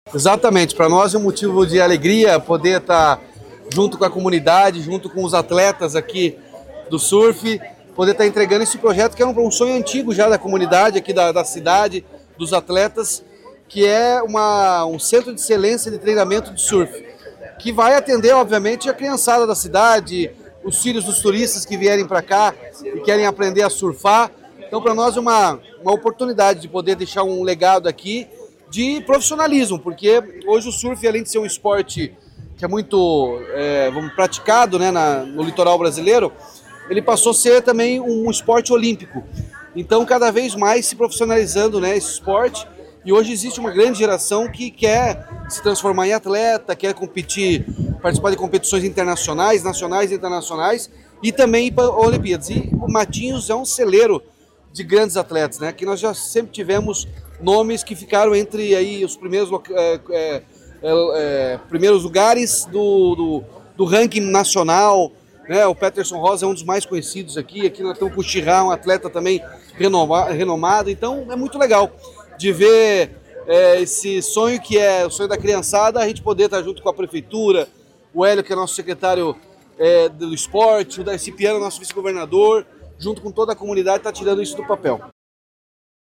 Sonora do governador Ratinho Junior sobre o início das obras de escola de surfe para crianças em Matinhos